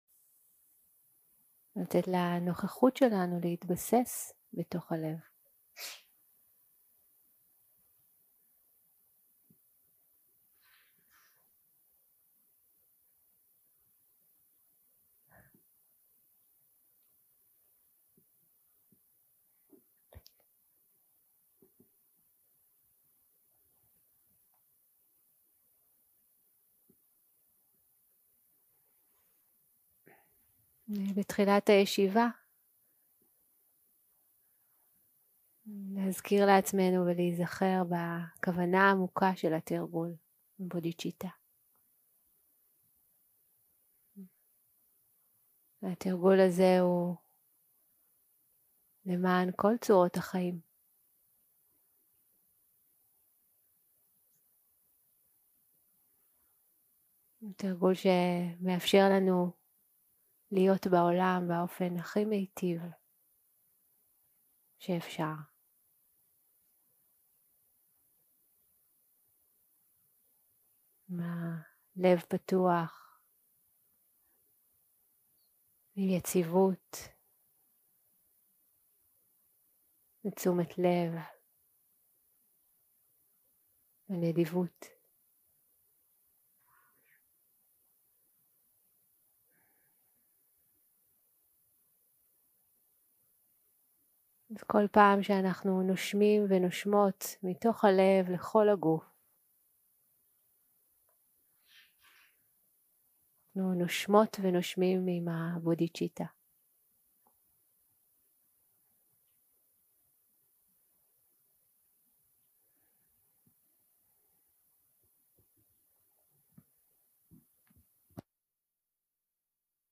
יום 3 - הקלטה 5 - בוקר - מדיטציה מונחית - ישיבת בוקר שקטה - כוונת הבודהיצ'יטה
יום 3 - הקלטה 5 - בוקר - מדיטציה מונחית - ישיבת בוקר שקטה - כוונת הבודהיצ'יטה 0:00 0:00 סוג ההקלטה: Dharma type: Guided meditation שפת ההקלטה: Dharma talk language: Hebrew